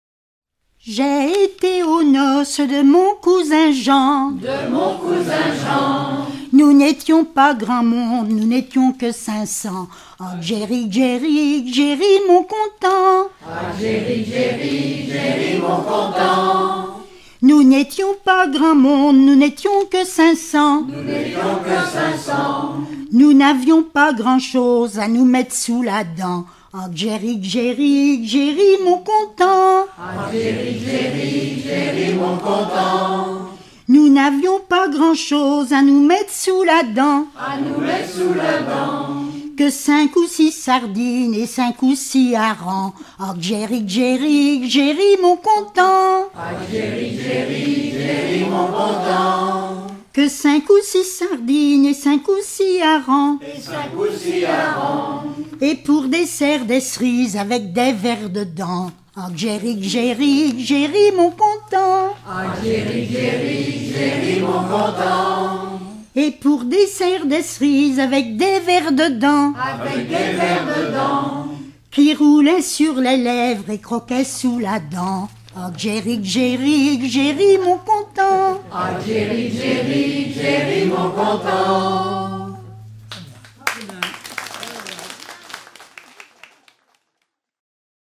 Région ou province Bocage vendéen
Genre laisse
Catégorie Pièce musicale éditée